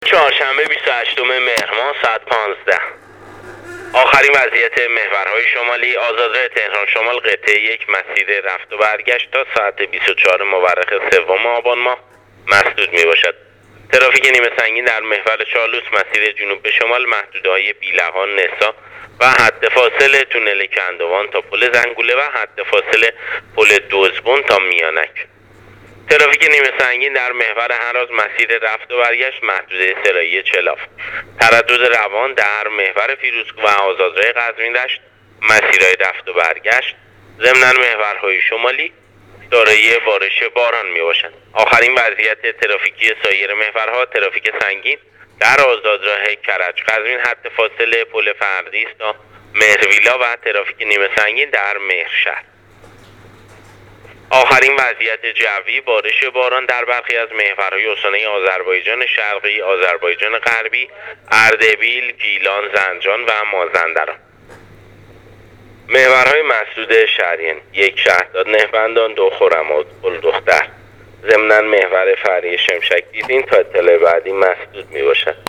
گزارش رادیو اینترنتی از آخرین وضعیت ترافیکی جاده‌ها تا ساعت ۱۵ بیست‌و هشتم مهر؛